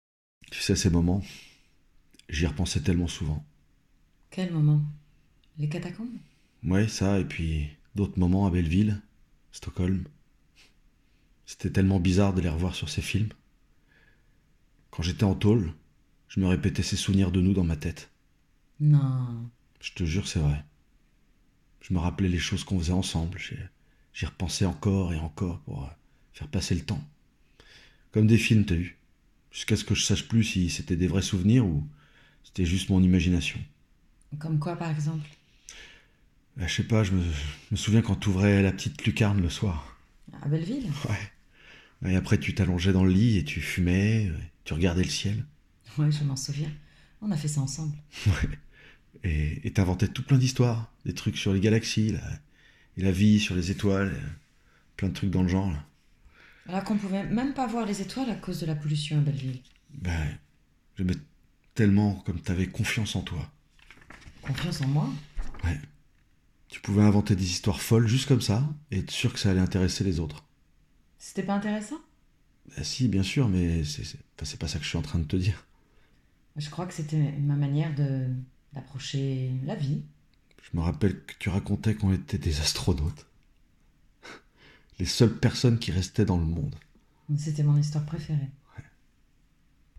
Bandes-son
Puissant et intense dans mon jeu, j'interprète également des registres plus sensibles et nuancés.
40 - 55 ans - Ténor